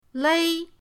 lei1.mp3